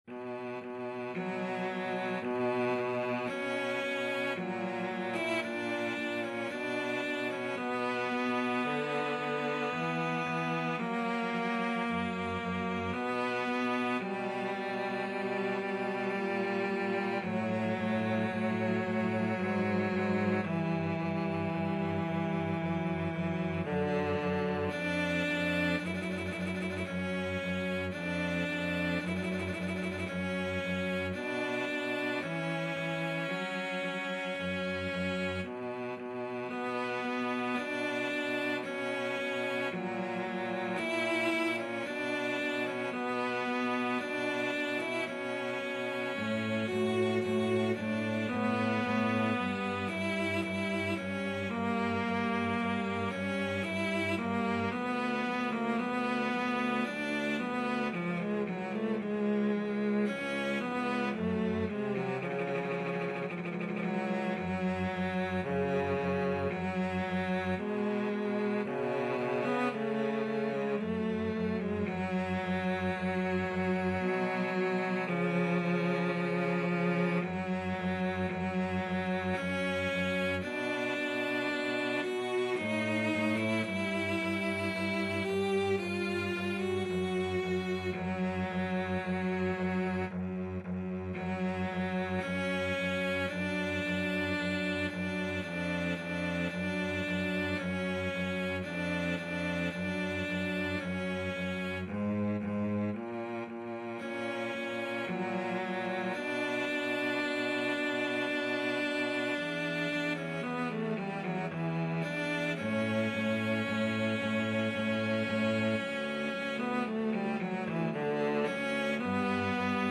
Cello 1Cello 2
3/4 (View more 3/4 Music)
= 56 Largo
Classical (View more Classical Cello Duet Music)